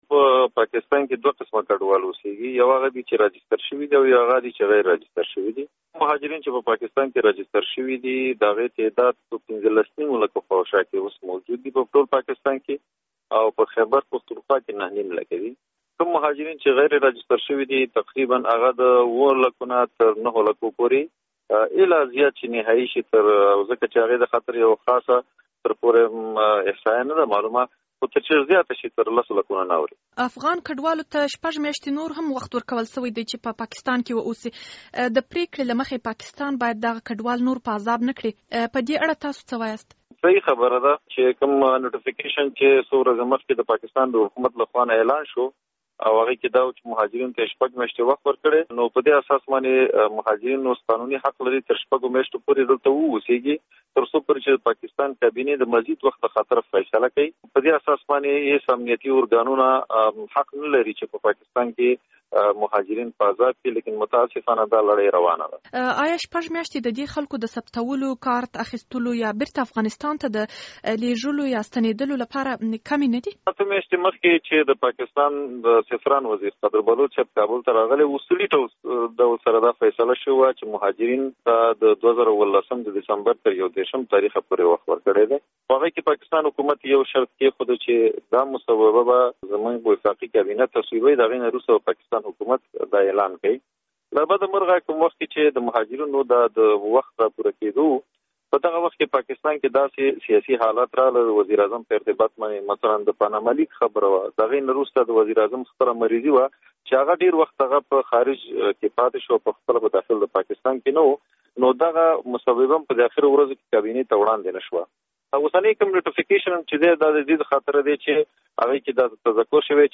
مرکه
له عبدالحمید جلیل سره مرکه